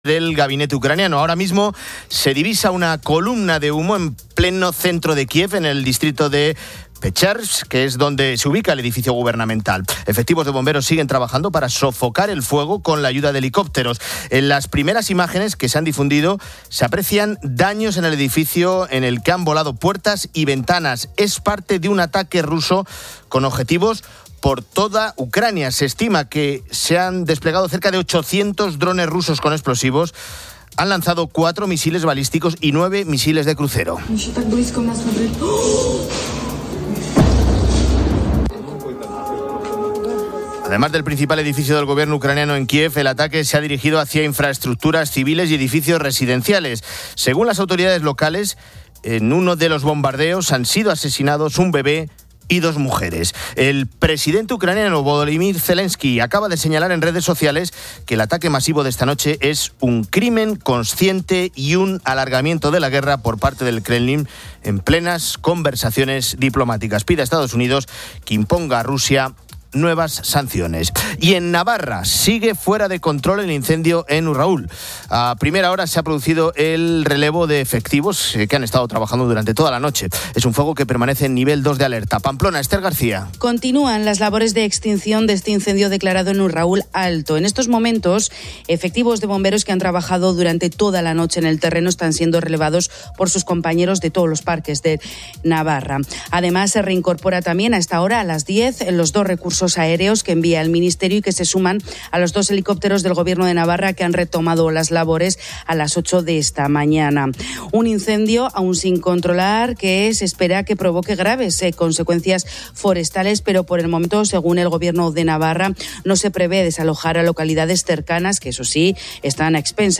Fin de Semana 10:00H | 07 SEP 2025 | Fin de Semana Editorial de Cristina López Schlichting sobre la luna de sangre de esta noche, causada por el eclipse total lunar, la crispación política y el partido de Alcaraz.